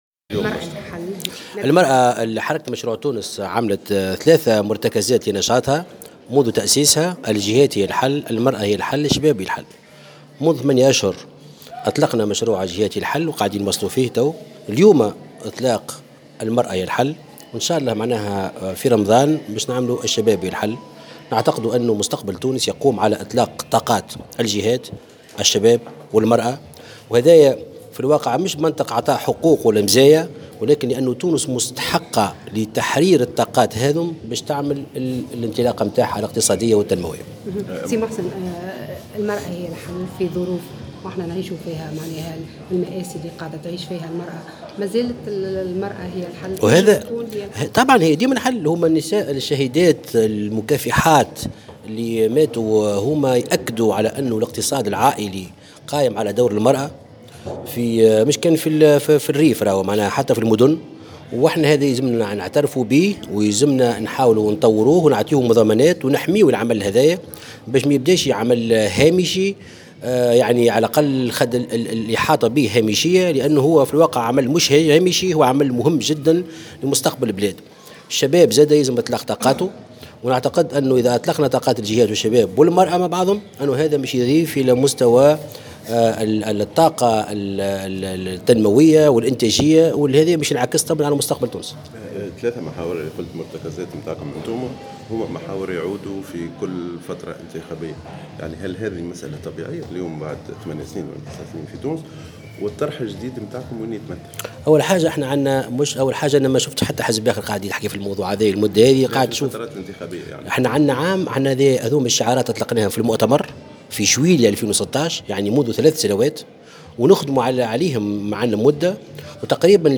أعلن محسن مرزوق، اليوم الأربعاء، على هامش تنظيم حزبه "مشروع تونس" ندوة حول المرأة بنابل ، استعداده لتجميع العائلة الوطنية حتى ولو كان ذلك على حساب اي طموح شخصي له، حسب تعبيره.
وأكّد في تصريح لمراسلة "الجوهرة أف أم"